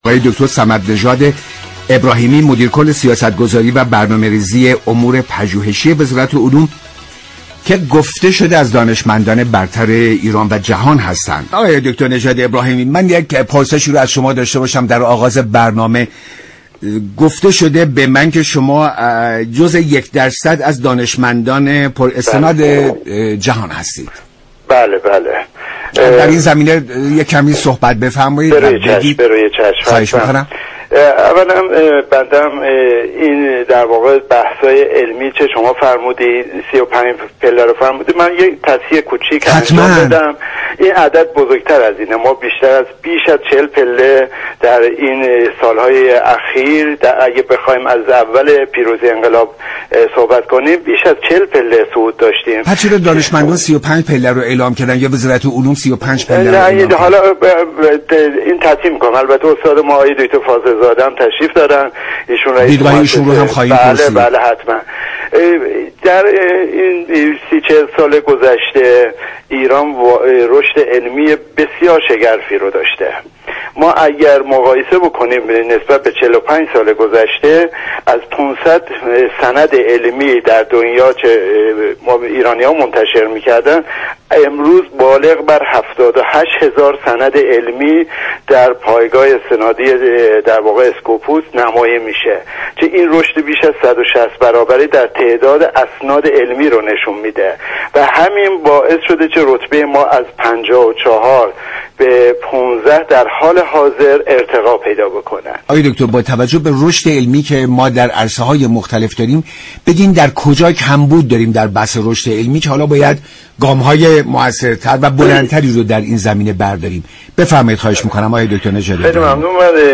به گزارش شبكه رادیویی ایران، مدیر كل سیاستگذاری و برنامه ریزی امور پژوهشی وزارت علوم در برنامه ایران امروز از پیشرفت های علمی ایران صحبت كرد و گفت: علم و فناوری ایران در سال‌های اخیر بیش از 40 پله صعود داشته است.